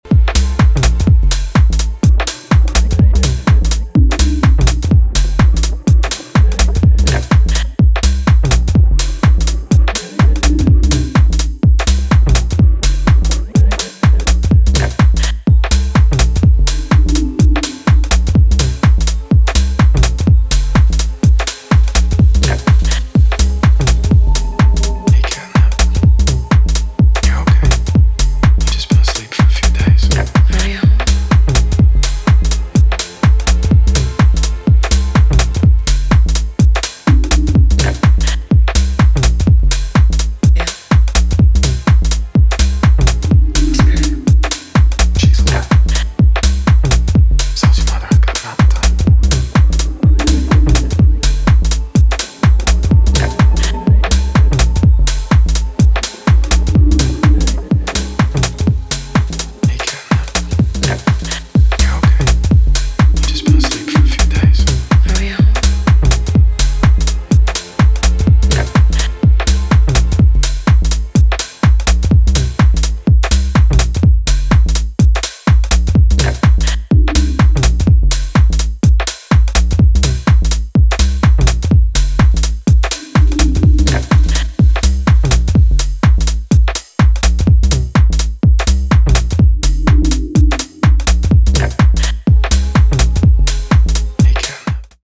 ソリッドなグルーヴにヴォイスサンプルや仄かなパッドを配した
今回は全体的にダークでトリッピーなムードが際立っており、深い時間に良質な溜めを産んでくれるでしょう。